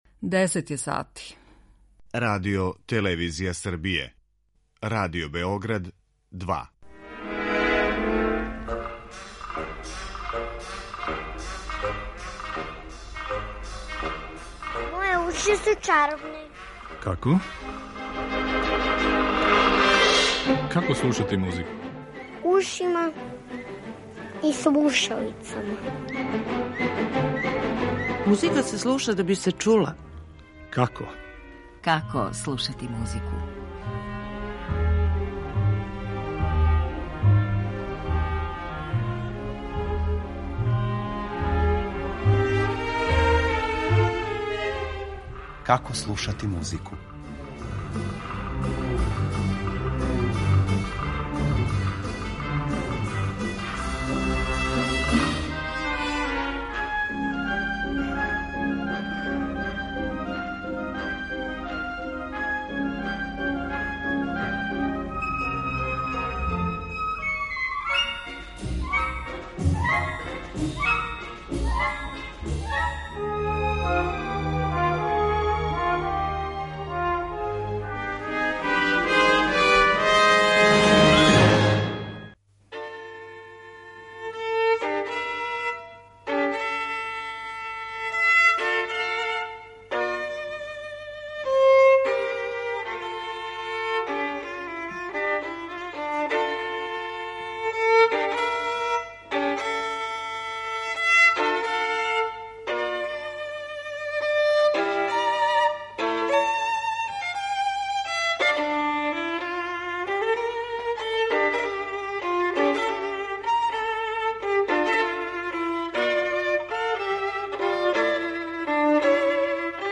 [ детаљније ] Све епизоде серијала Аудио подкаст Радио Београд 2 Жак Офенбах: Концерт за виолончело Винорел Прича о Великом издајству 1204.